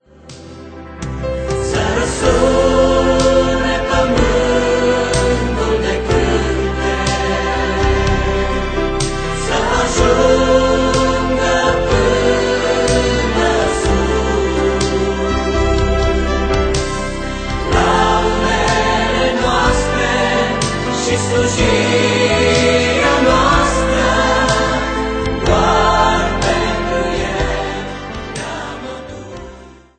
piese care aduc un suflu de nostalgie albumului.